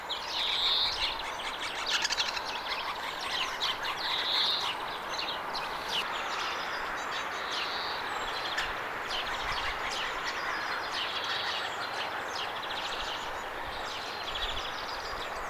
käpytikkojen keväistä ääntelyä jotain
kapytikkojen_kevaista_kisailuaantelya_tai_jotain_ehka_pariutumiseen_liittyvaa_aantelya.mp3